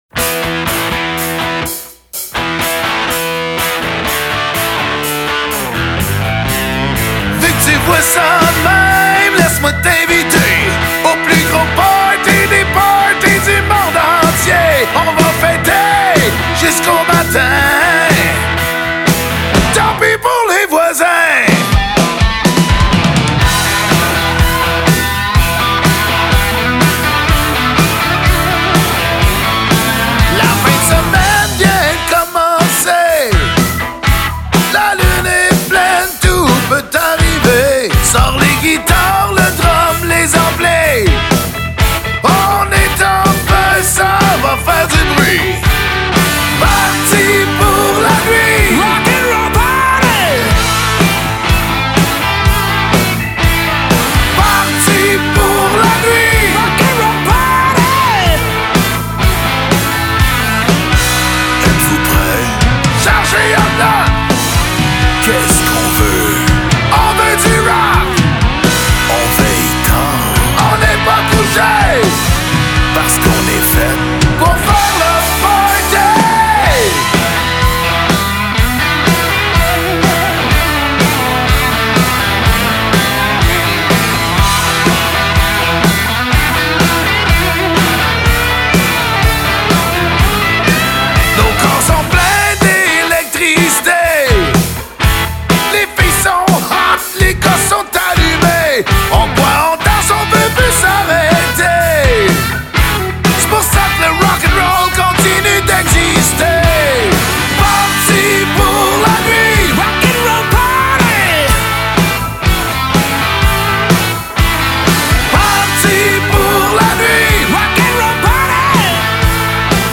single Nouvel album rock
La  toune  de  party  la  plus  rock  de  l’été
BASSE, VOIX
GUITARES, VOIX
BATTERIE, PERCUSSIONS, VOIX
PIANO, B-3